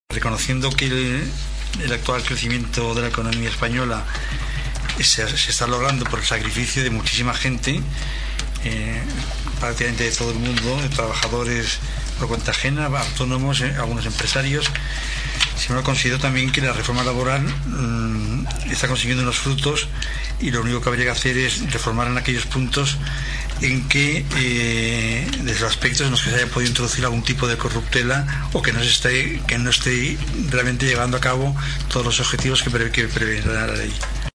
El ple de l’Ajuntament de Tordera aprova una moció al voltant de les empreses multiserveis
El regidor no adscrit, Jose Carlos Villaro, es va abstenir perquè considera que no cal derogar tota la reforma laboral.